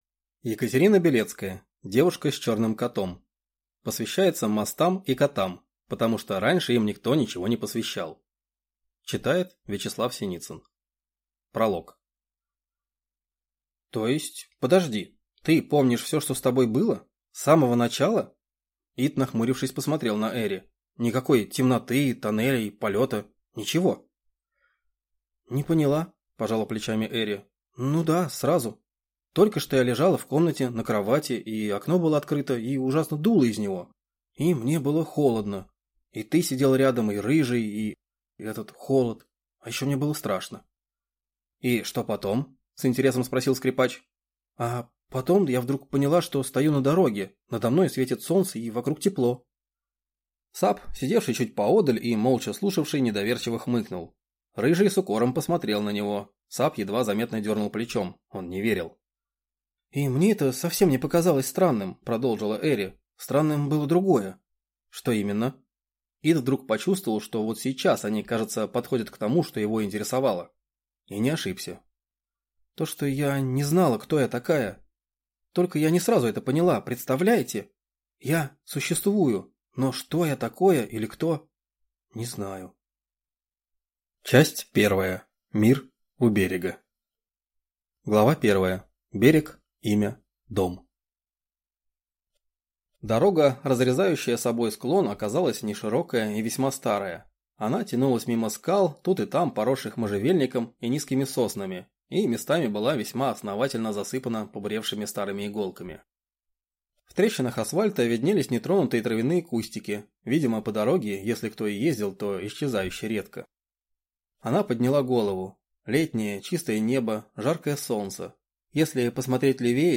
Аудиокнига Девушка с черным котом | Библиотека аудиокниг